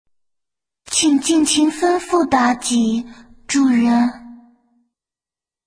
SFX王者荣耀妲己音效下载
SFX音效